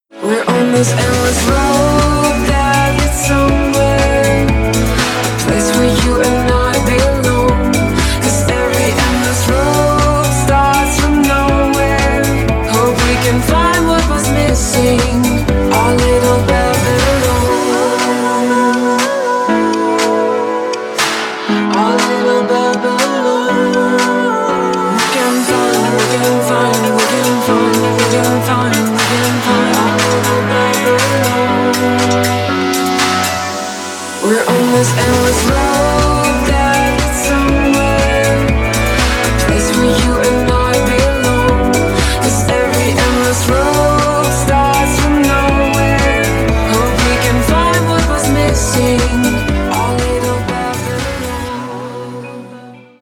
• Качество: 320, Stereo
dance
vocal